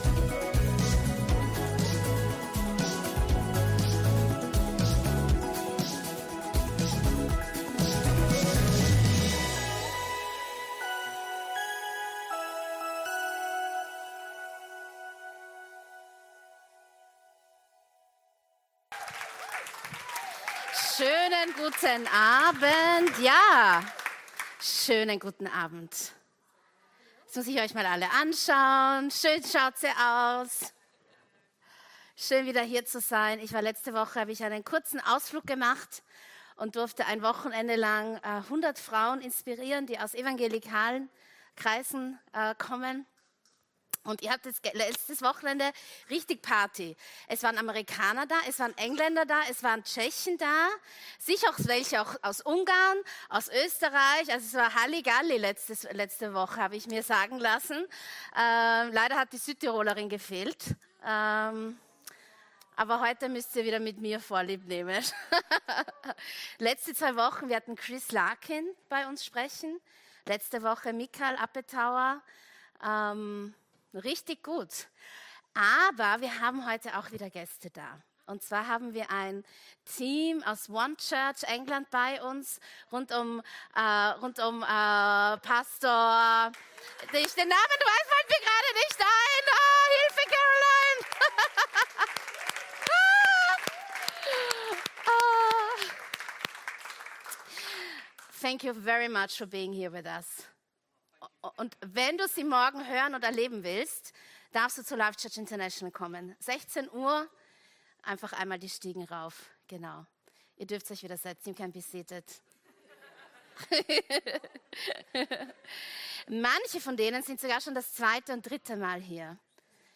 Gottesdienst live aus der LIFE Church Wien.